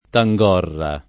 [ ta jg0 rra ]